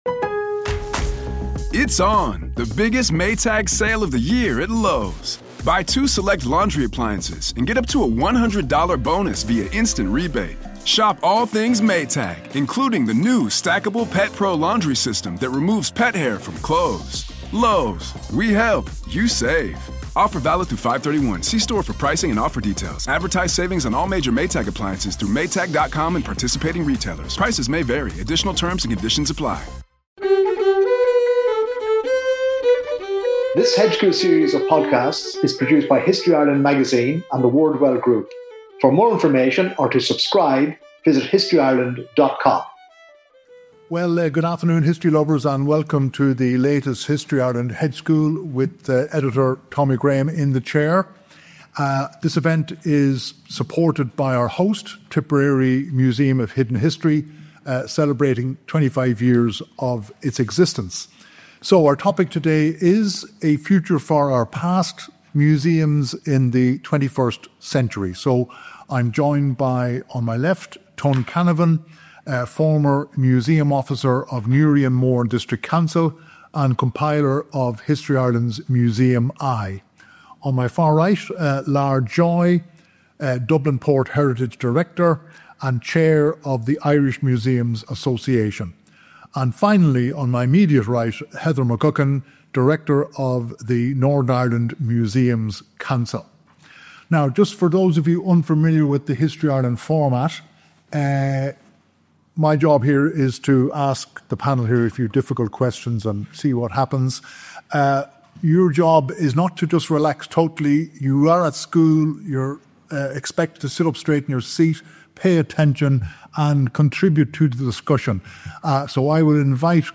Recorded live on Sat 17 May 2025, @ Tipperary Museum of Hidden History, Mick Delahunty Square, Clonmel Share Facebook X Subscribe Next Housing in Ireland—a history of dysfunction?